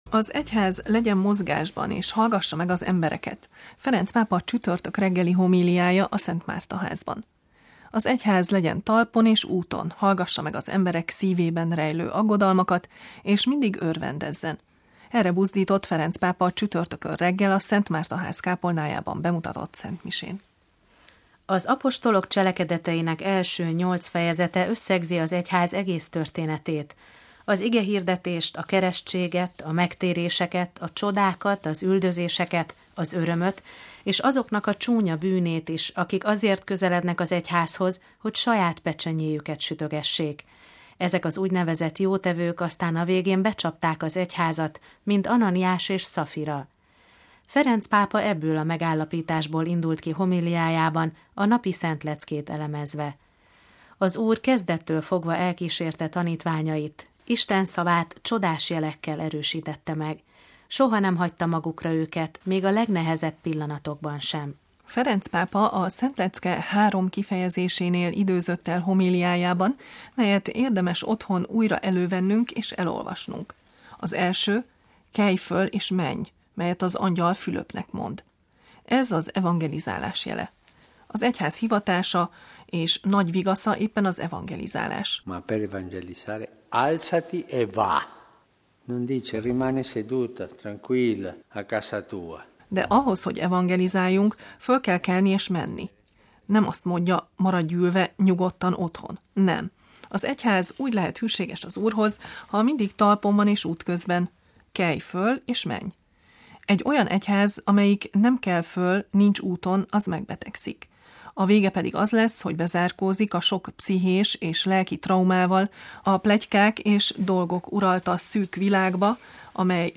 Az egyház hallgassa meg az embereket: Ferenc pápa csütörtök reggeli homíliája a Szent Márta-házban
Az egyház legyen talpon és úton, hallgassa meg az emberek szívében rejlő aggodalmakat, és mindig örvendezzen. Erre buzdított Ferenc pápa csütörtökön reggel, a Szent Márta-ház kápolnájában bemutatott szentmisén.